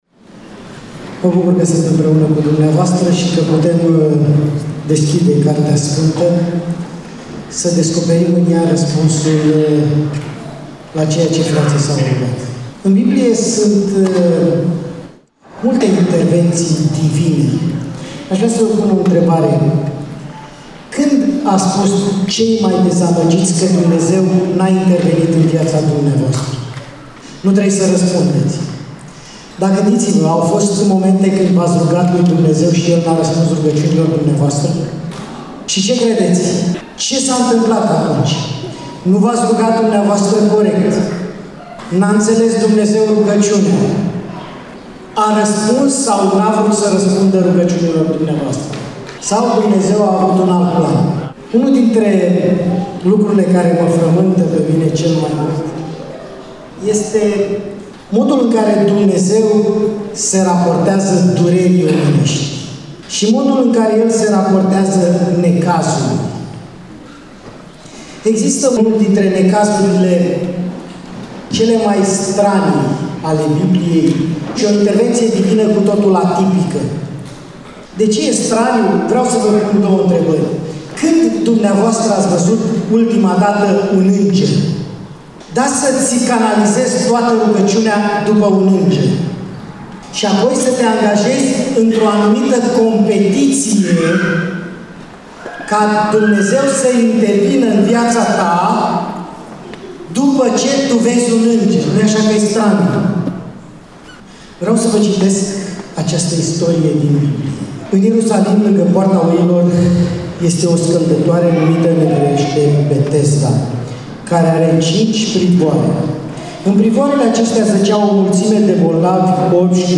EMISIUNEA: Predică DATA INREGISTRARII: 25.04.2026 VIZUALIZARI: 6